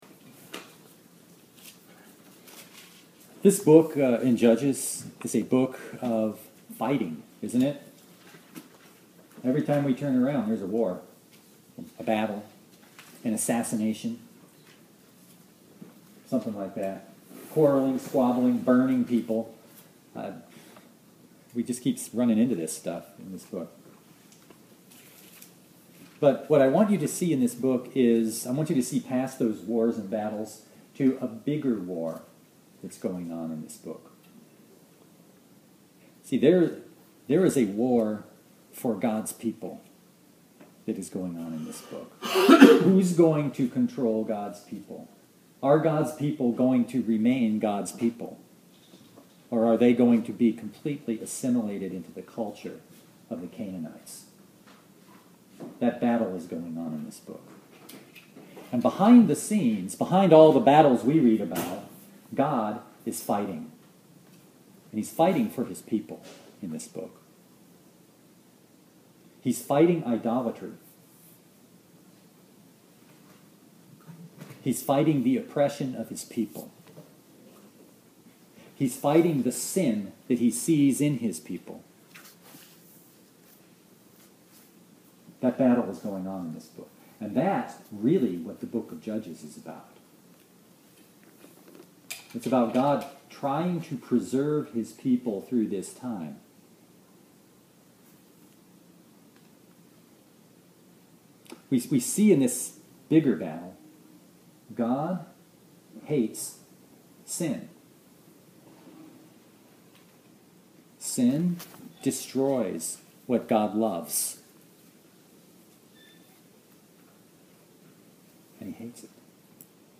Bible Text: Judges 15:1-20 | Preacher